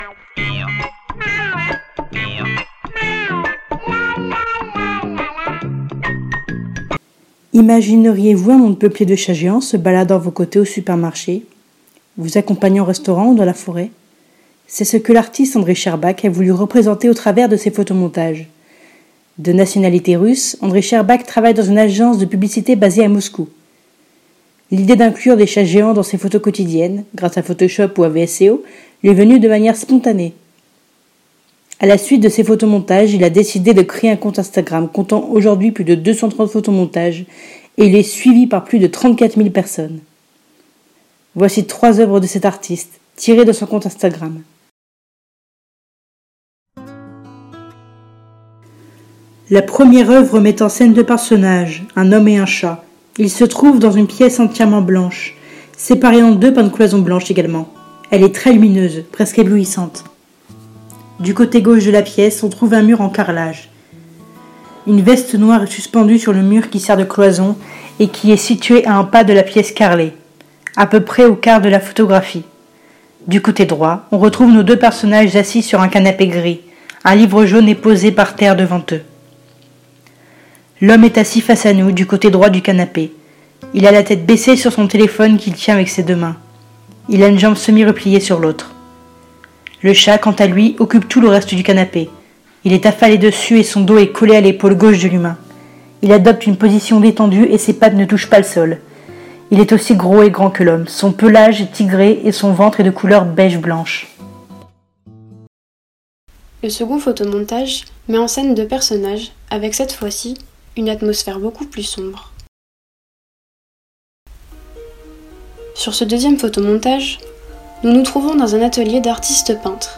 Pendant les mois d'octobre et novembre 2020, nous avons travaillé à l'audiodescription d'images fixes, en explorant le travail d'Andrey Shcherbak. Les participant·e·s à l'atelier ont mené cette activité en groupes, chacun d'eux proposant une visite virtuelle parmi les œuvres mises en ligne par l'artiste.